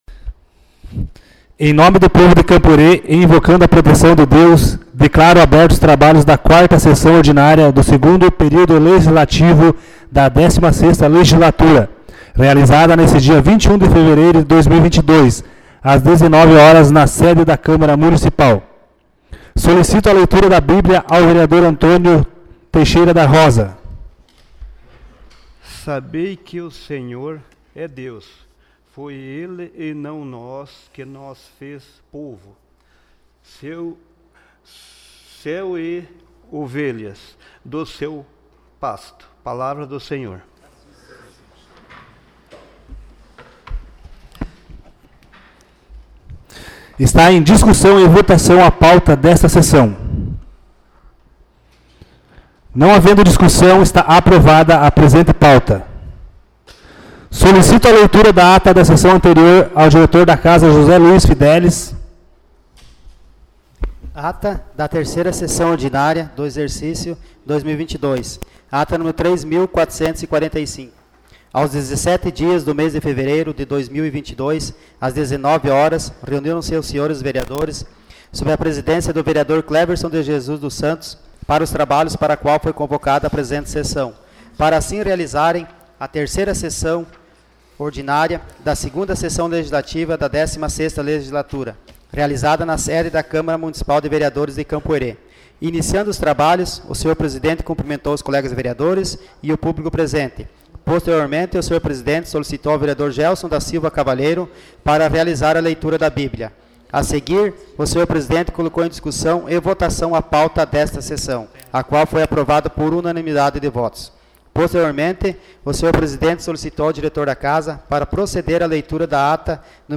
Sessão Ordinária 21 de fevereiro de 2022